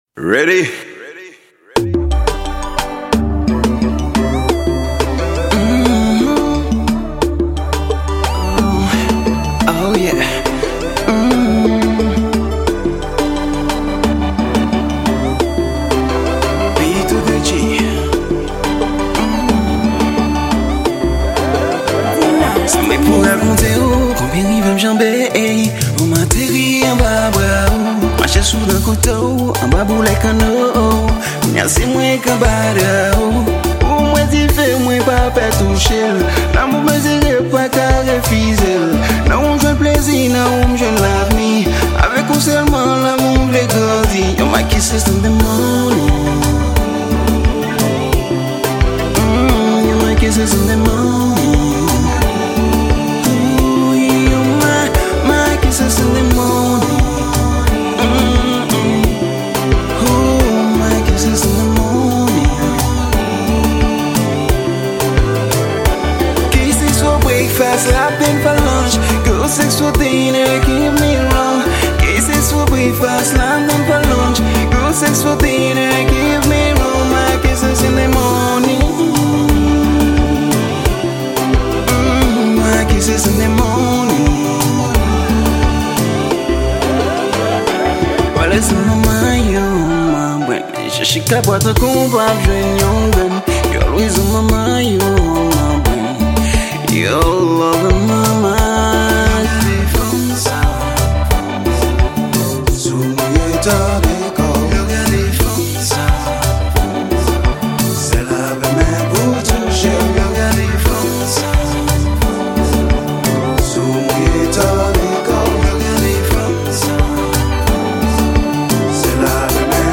Genre: Compas